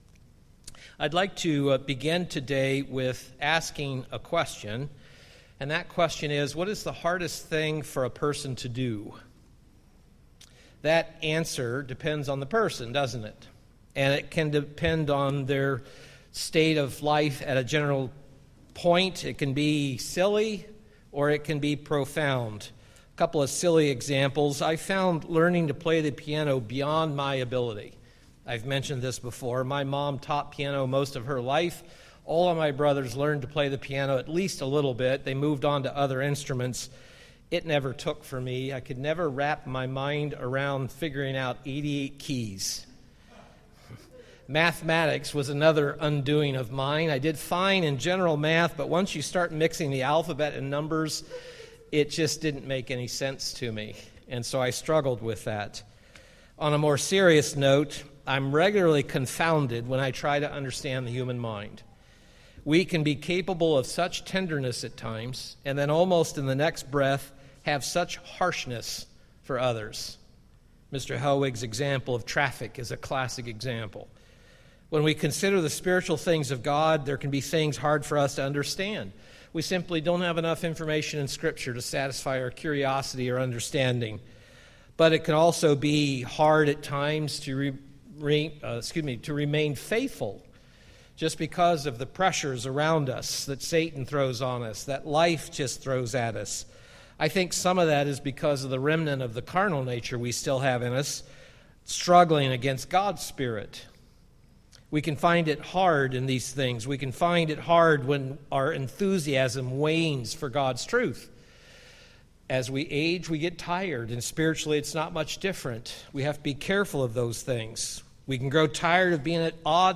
This is part 2 of the sermon series I started a few weeks ago on the letters to the Churches in Revelation 2 & 3. Today we are going to consider the churches of Pergamos, Thyatira, and Sardis.
Given in Milwaukee, WI